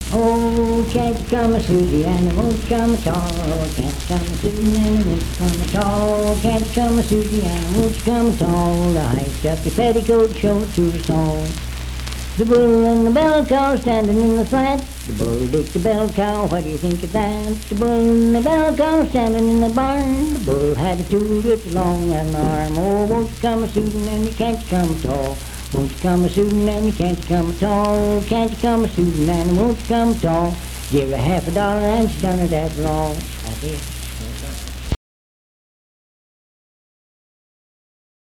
Unaccompanied vocal music
Performed in Sandyville, Jackson County, WV.
Bawdy Songs
Voice (sung)